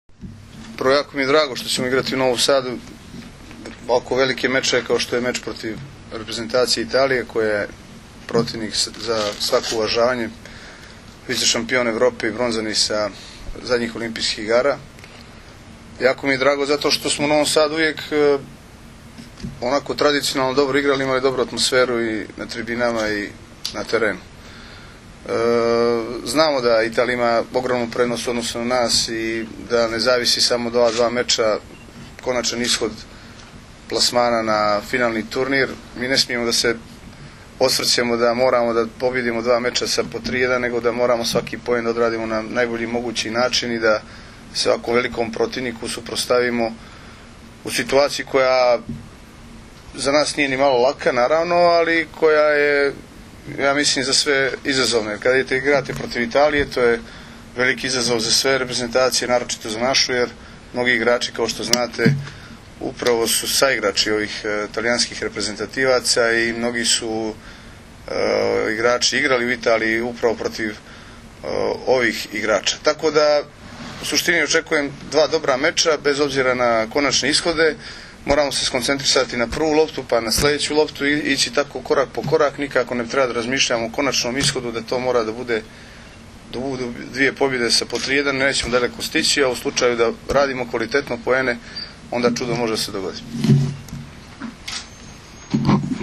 U hotelu Park u Novom Sadu danas je održana konferencija za novinare uoči utakmica poslednjeg, V V vikenda B grupe XXIV Svetske lige 2013. između Srbije i Italije.
IZJAVA IGORA KOLAKOVIĆA